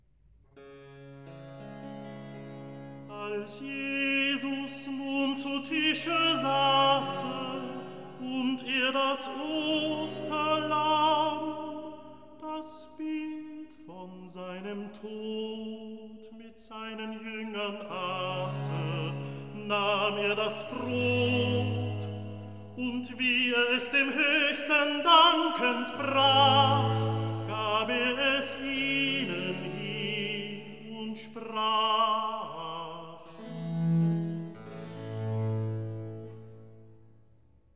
Recitativo evangelist